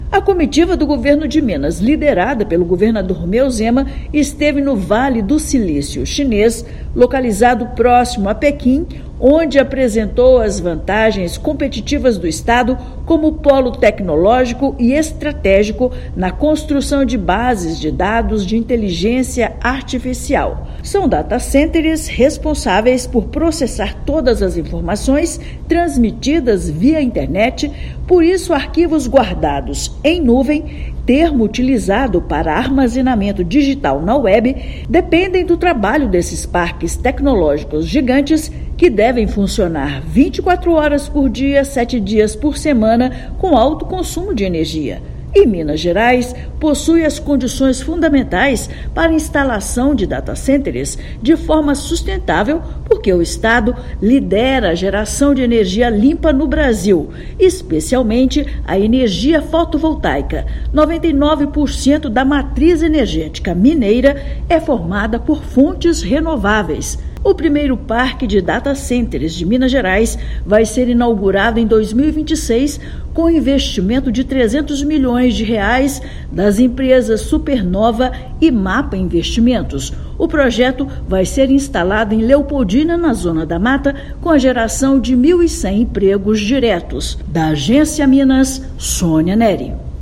Comitiva do Governo de Minas esteve com investidores e empresários no centro tecnológico de Zhongguancun, principal reduto de inovação do país chinês. Ouça matéria de rádio.